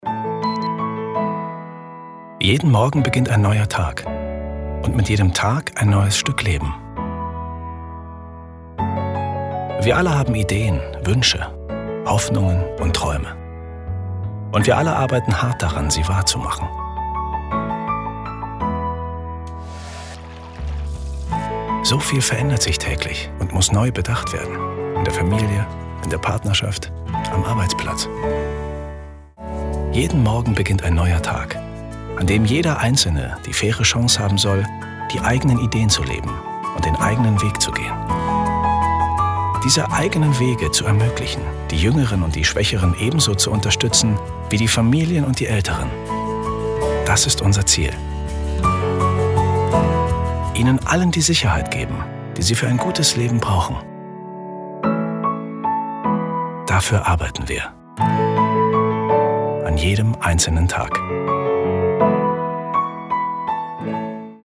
warme, klare, energetische Stimme, erfahren,kompetent mit viel Spaß an der Arbeit
Sprechprobe: Sonstiges (Muttersprache):